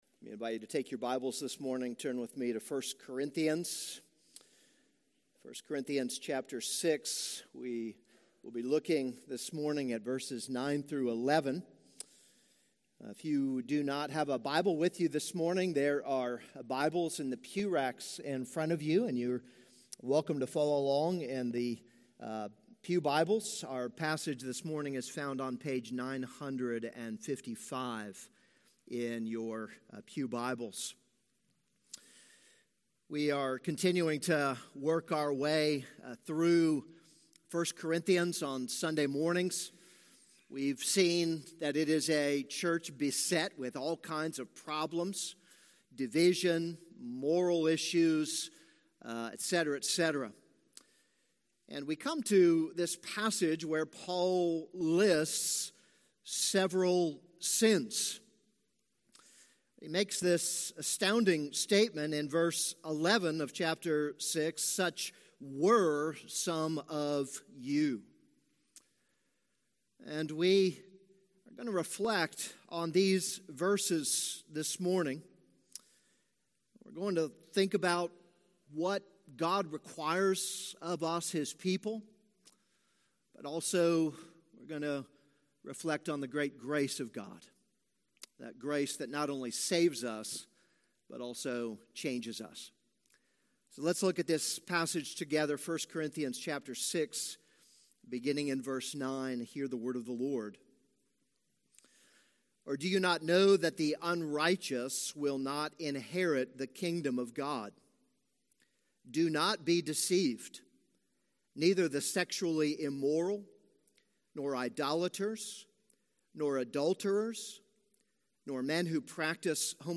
This is a sermon on 1 Corinthians 6:9-11.